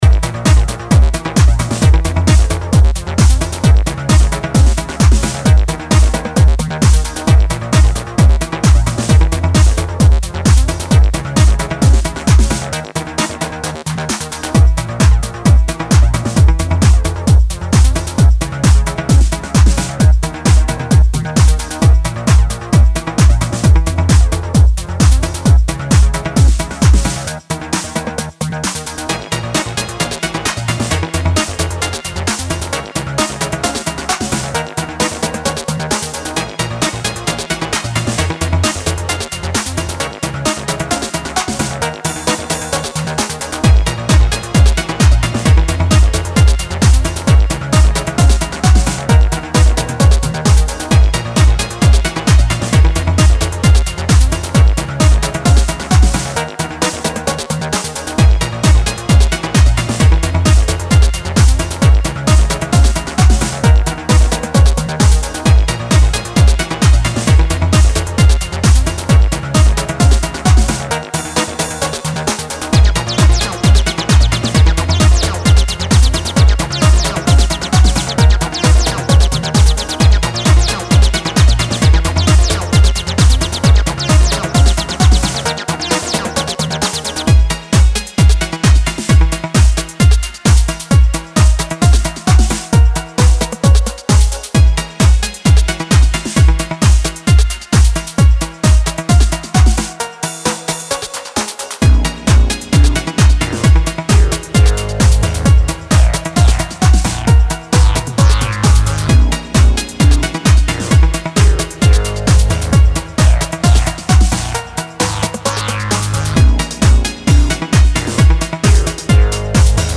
Enigmatic techno-wave excursion in eternal night.